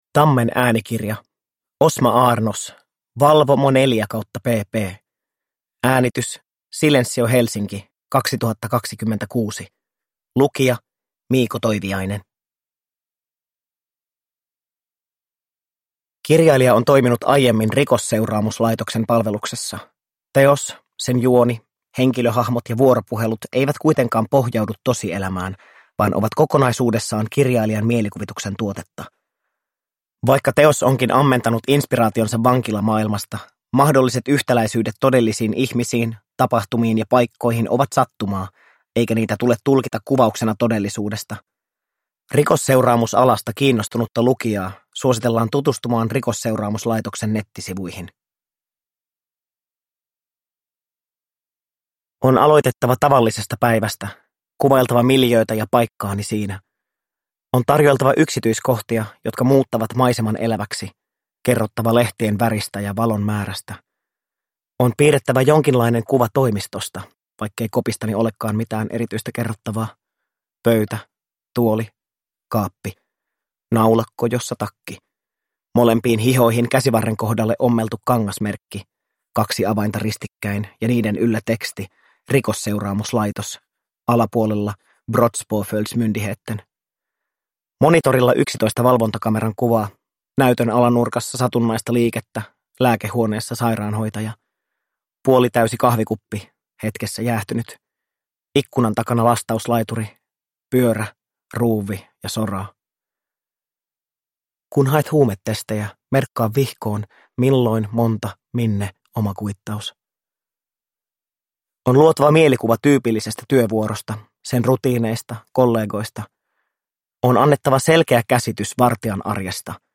Valvomo 4/pp – Ljudbok